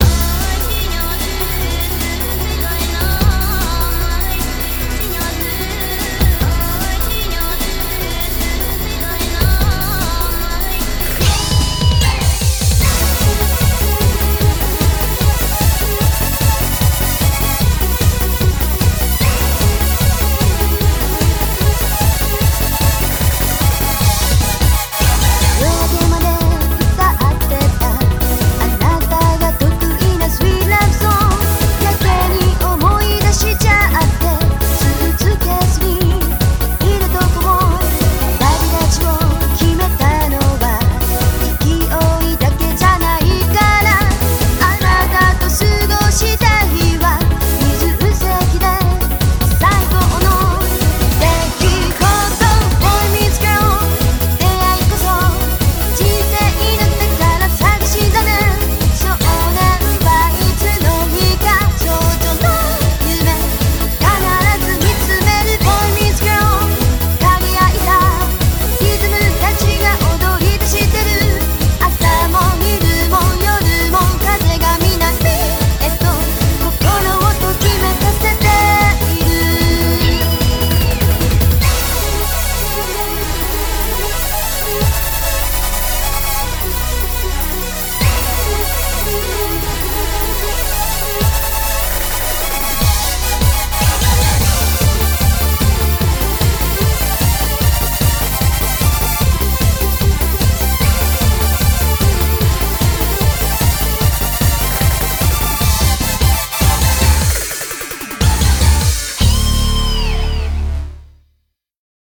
BPM150
Audio QualityPerfect (High Quality)
Eurobeat remix